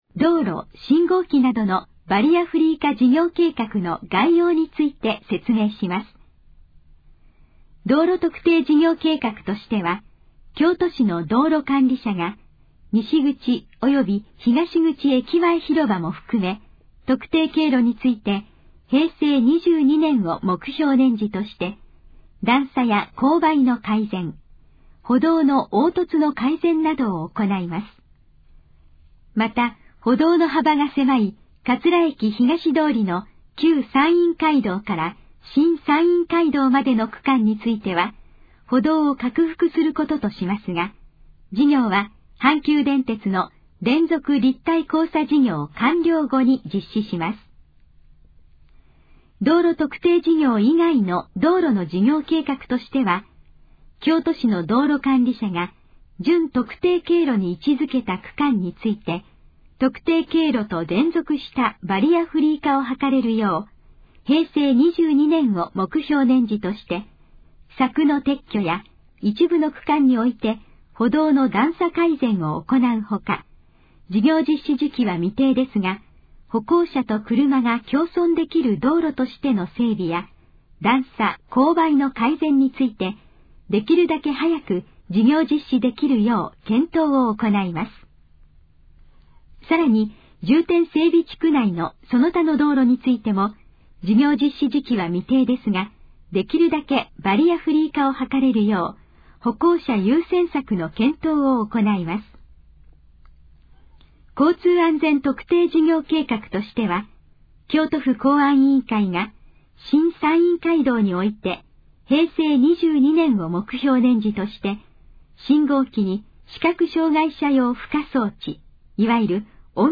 以下の項目の要約を音声で読み上げます。
ナレーション再生 約303KB